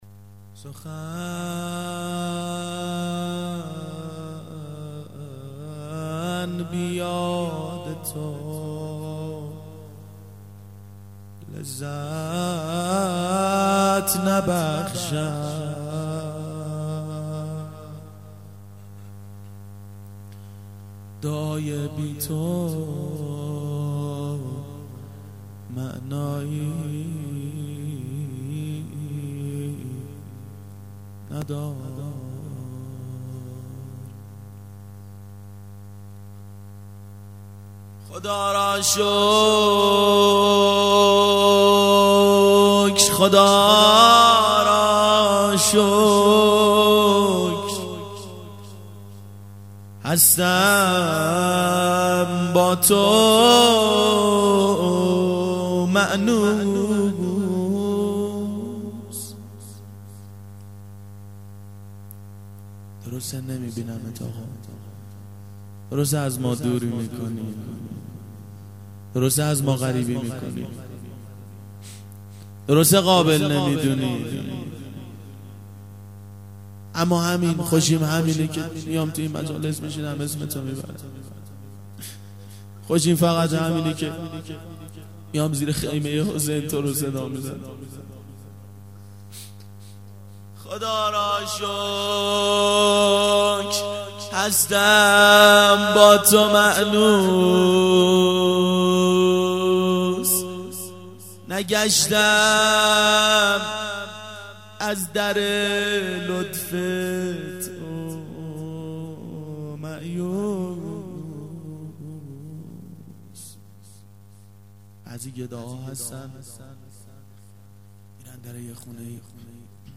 مناجات با امام زمان علیه السلام و روضه